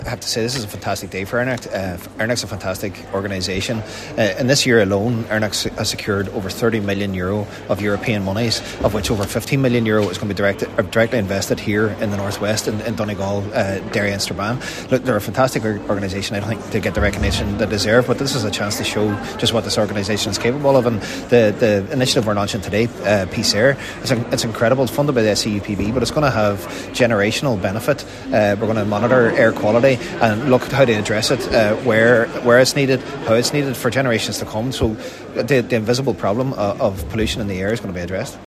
Cllr Jack Murray is ERNACT Chair……………